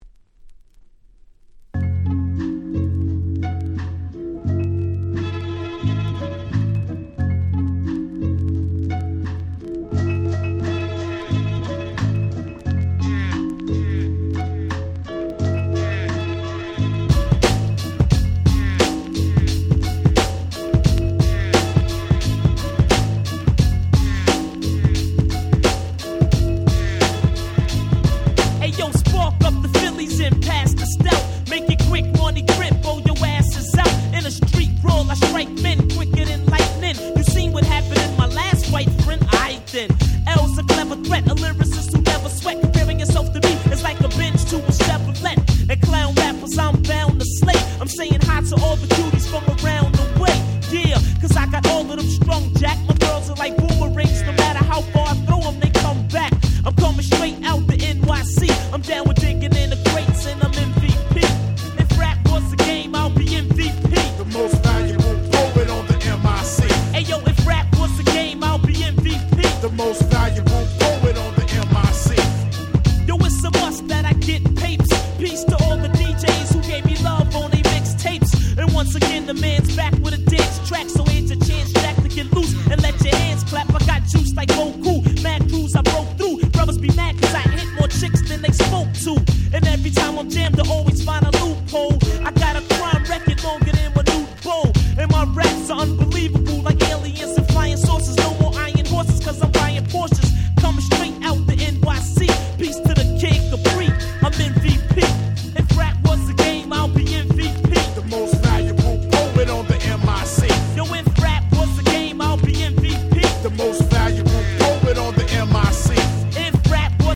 【Condition】C (薄いスリキズ多めですがDJ Play可。試聴ファイルをご確認願います。)